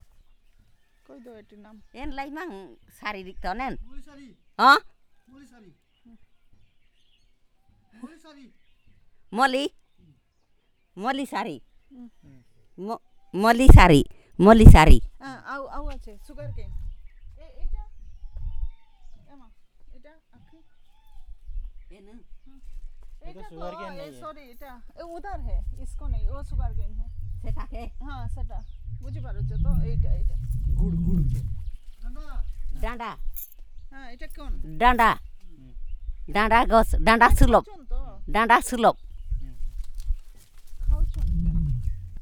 Elicitation of words about flowers and related and trees